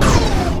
enemyshoot.wav